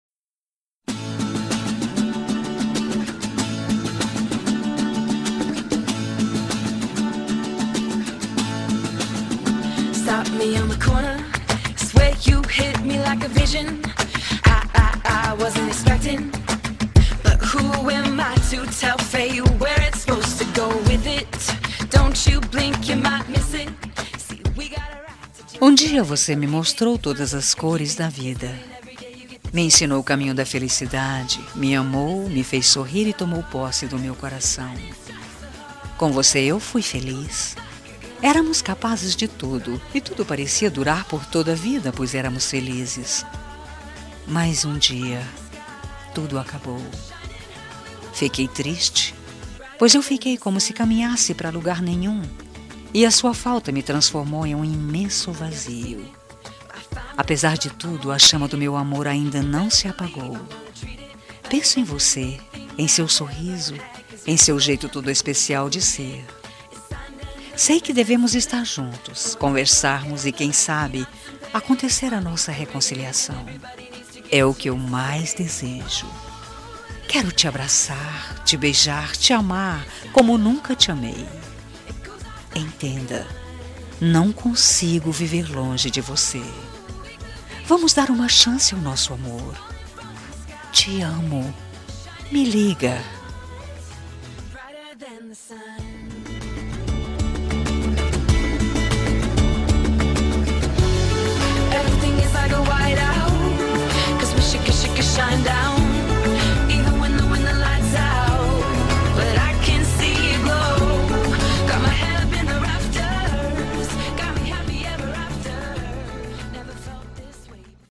Telemensagem de Reconciliação Romântica – Voz Feminina – Cód: 202018